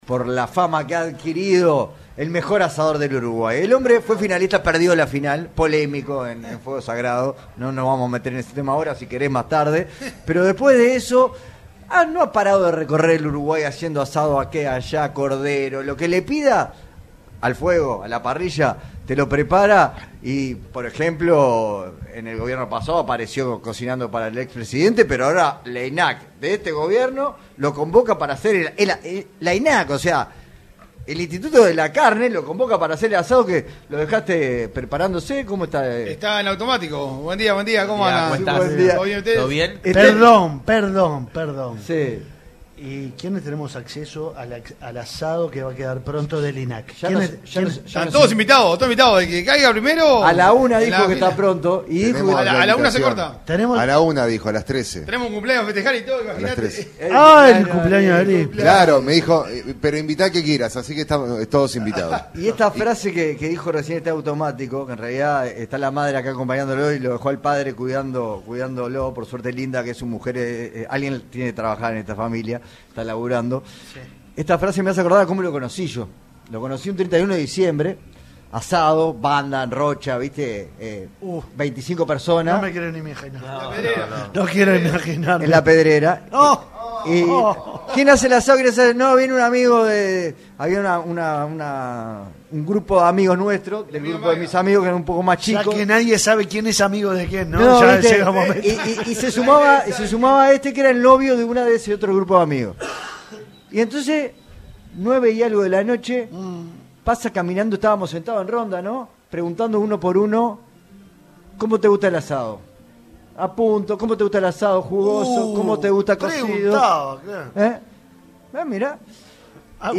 compartió con Punto de Encuentro en la Expo Rural del Prado el secreto para hacer un buen asado.